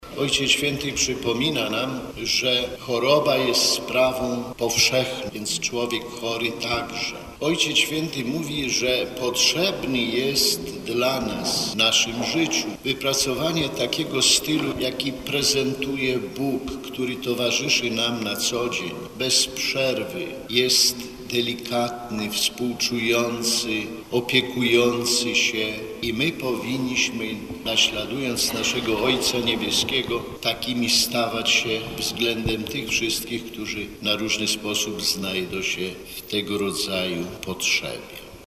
Ordynariusz warszawsko-praski nawiązywał również do orędzia Ojca Świętego Franciszka na XXXI Światowy Dzień Chorego, które opiera się na przypowieści o miłosiernym Samarytaninie. Jak mówił bp Kamiński, w opiece nad chorymi powinniśmy naśladować właśnie Samarytanina.